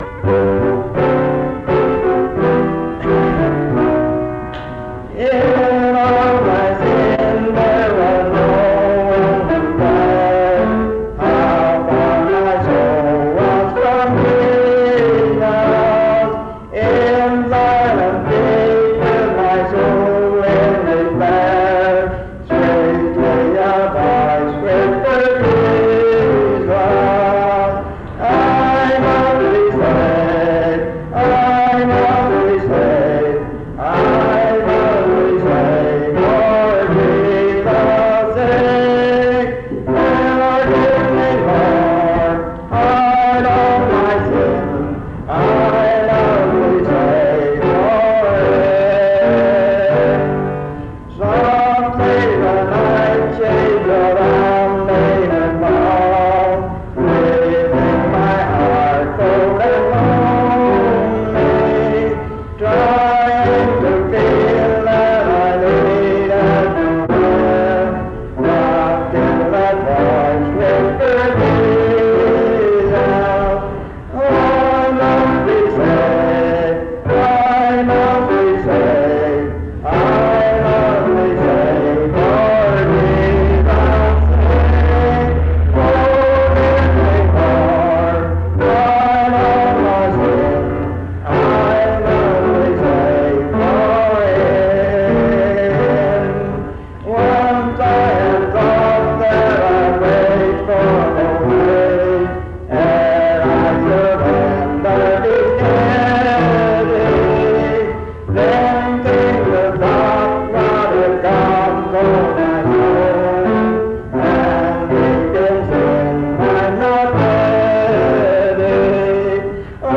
This recording is from the Monongalia Tri-District Sing.